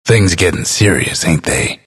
dlc_bastion_announcer_spectat_13.mp3